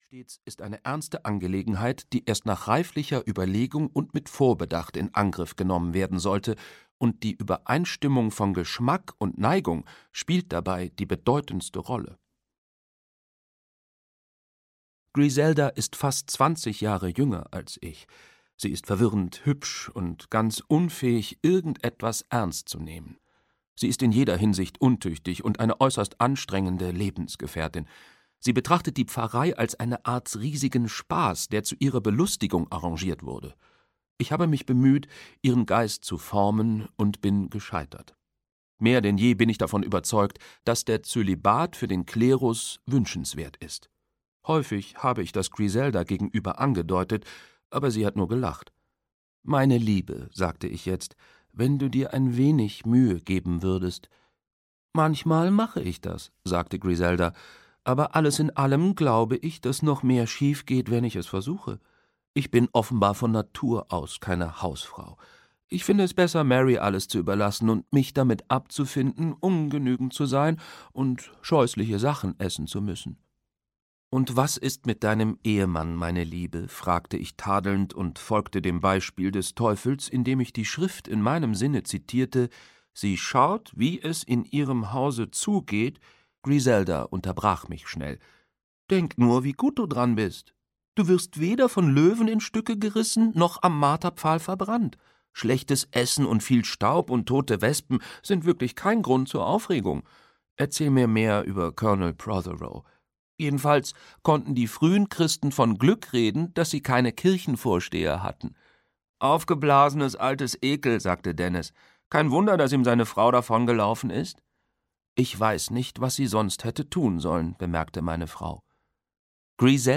Mord im Pfarrhaus (DE) audiokniha
Ukázka z knihy